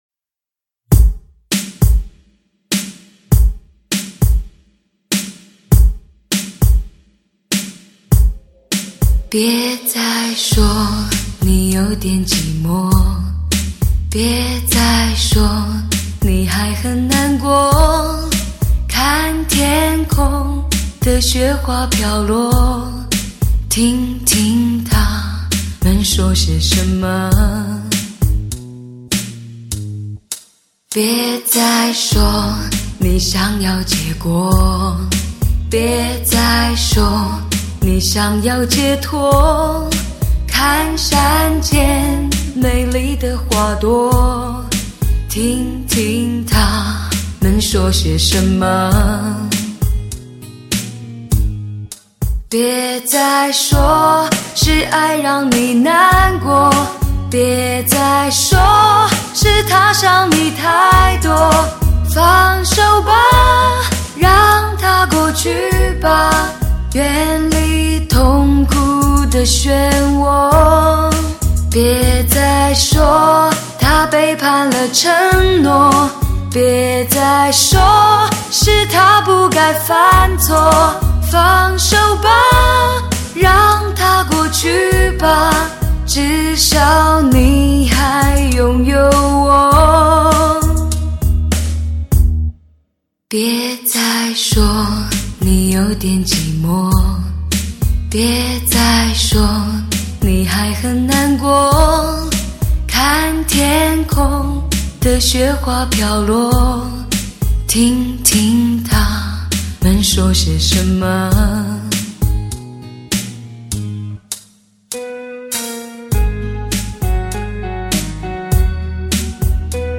专辑格式：DTS-CD-5.1声道
极品环绕专业靓声24BIT录音典范
为低音质MP3